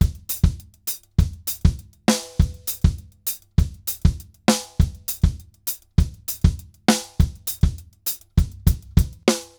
Drums_Baion 100_1.wav